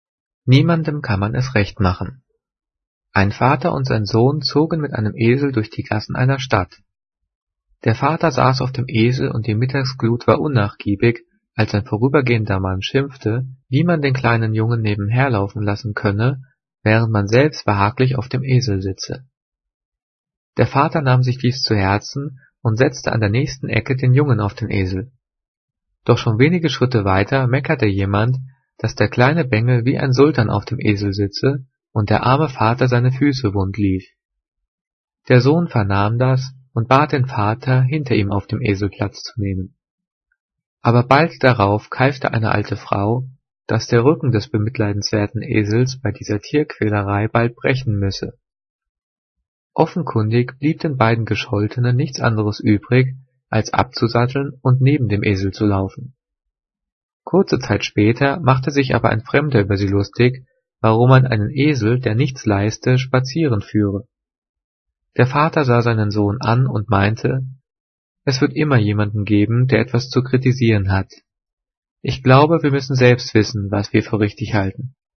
Gelesen: